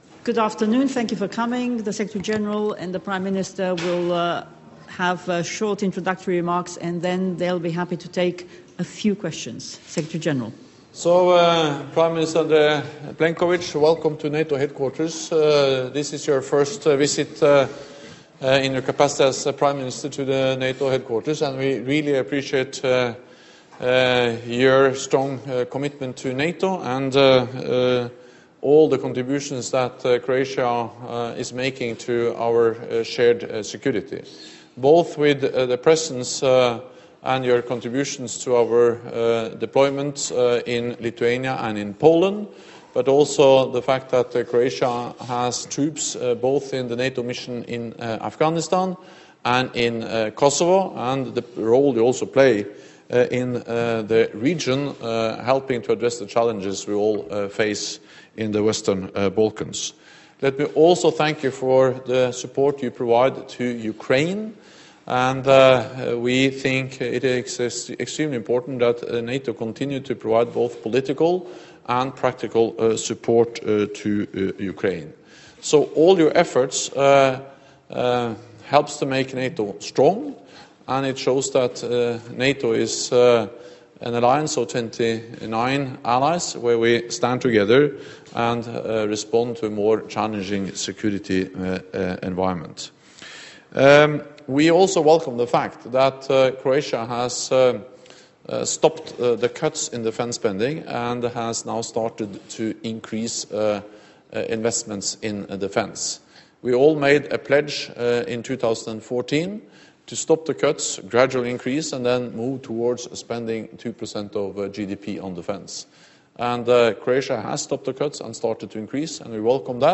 ORIGINAL - Joint press point with NATO Secretary General Jens Stoltenberg and the Prime Minister of Croatia, Andrej Plenković